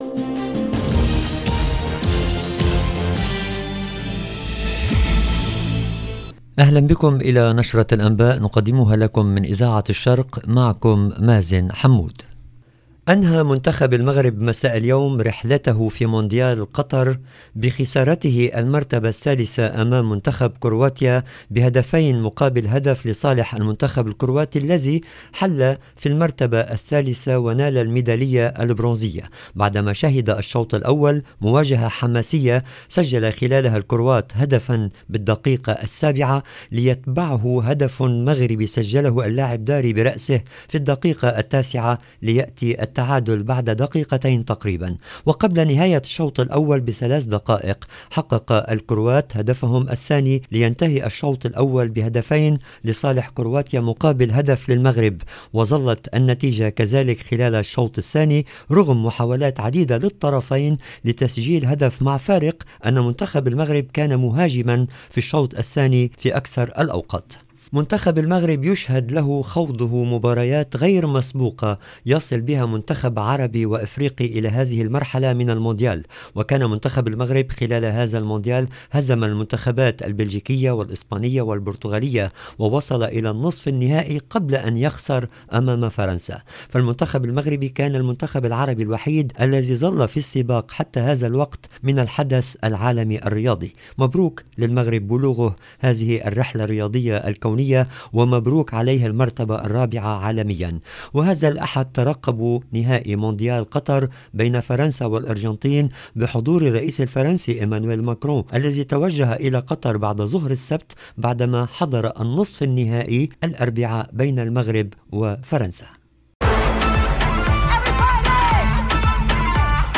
LE JOURNAL DU SOIR EN LANGUE ARABE DU 17/12/22